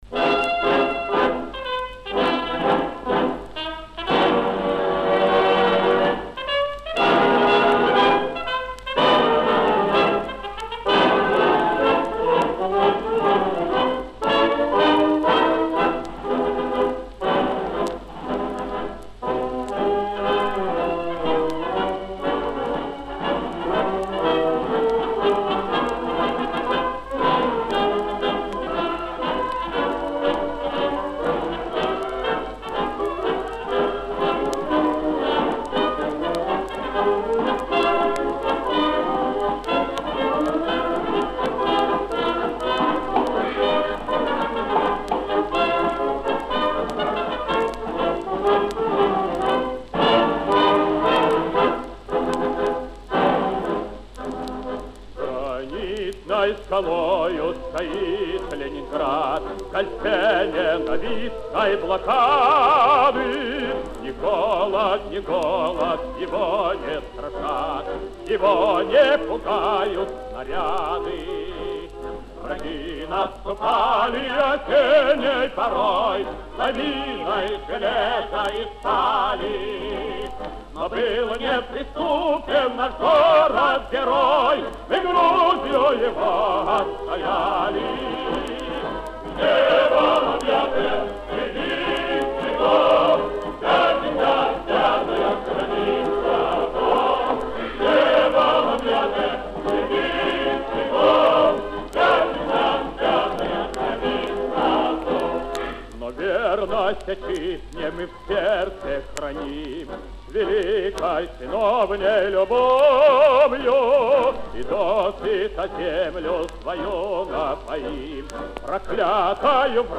Повышение качества песни, без металлического звука.